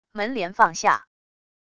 门帘放下wav音频